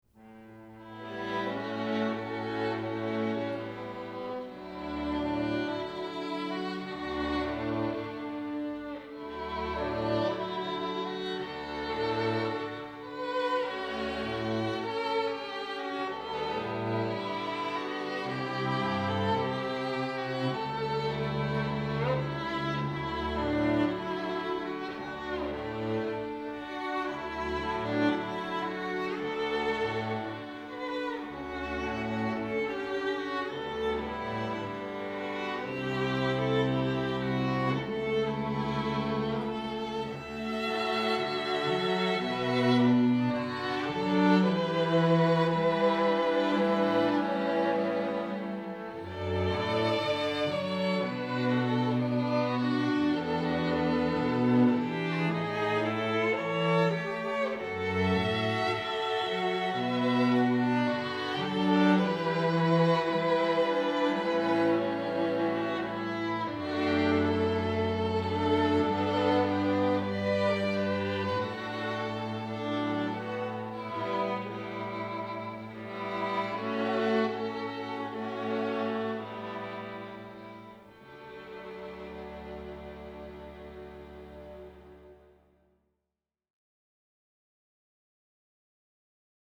Quatuor
Comédie musicale